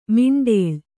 ♪ miṇḍēḷ